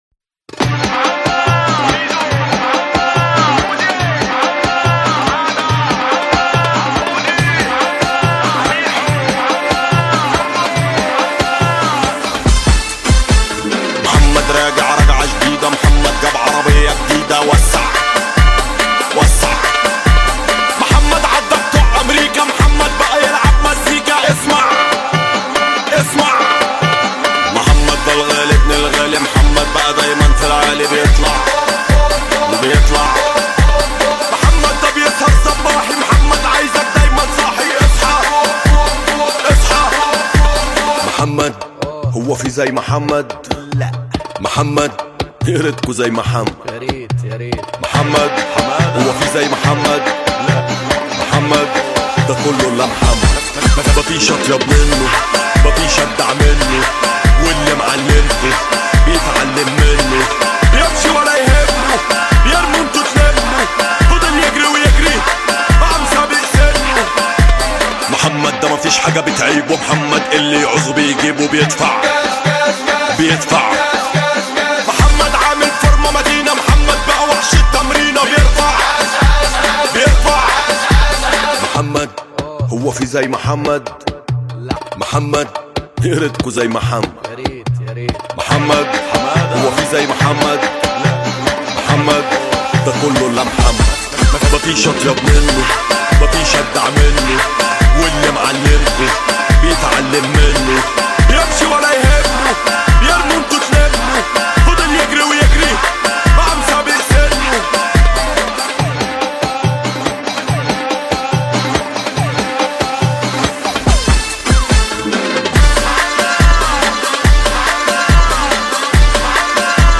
الأغنية تعتمد على إيقاع “الراب الشعبي” السريع
نوع الأغنية راب / هيب هوب شعبي